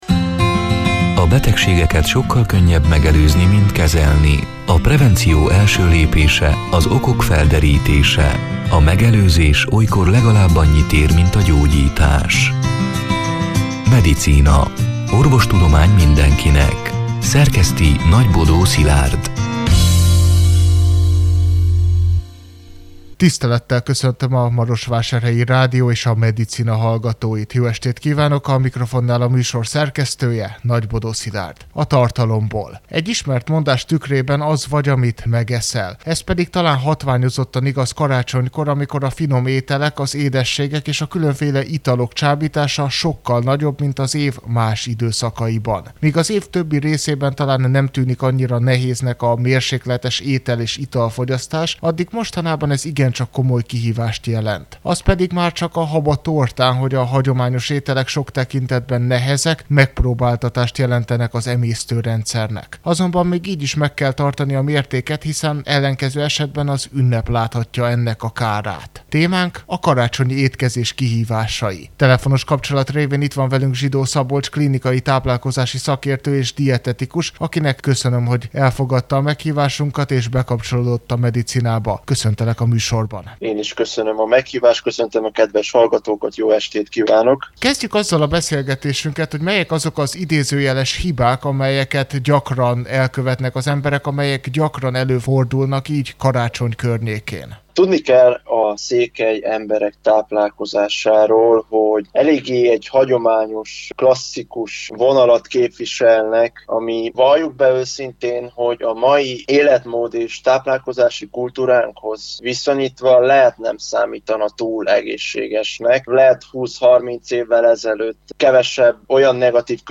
A Marosvásárhelyi Rádió Medicina (elhangzott: 2024. december 18-án, szerdán este nyolc órától) c. műsorának hanganyaga: